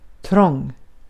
Ääntäminen
IPA: [trɔŋ]